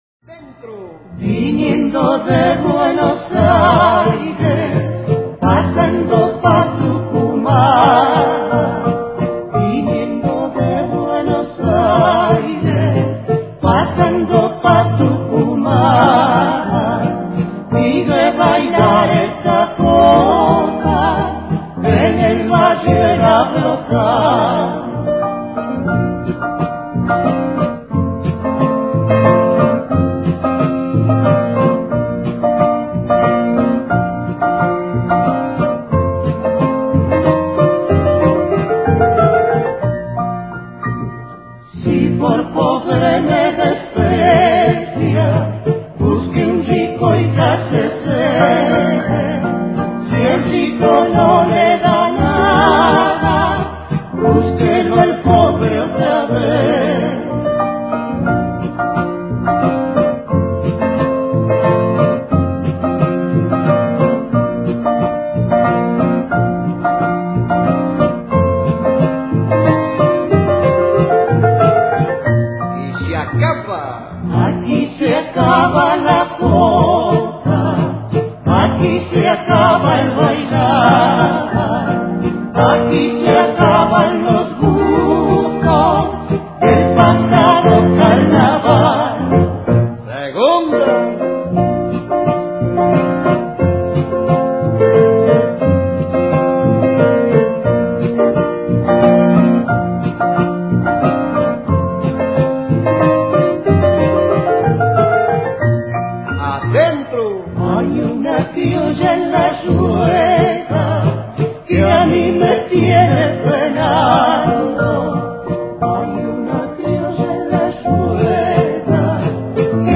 DANZAS FOLKLÓRICAS TRADICIONALES ARGENTINAS
No se trata sino de una adaptación criolla de la jota española.
Es una danza de galanteo, de pareja suelta e independiente y de movimiento vivo.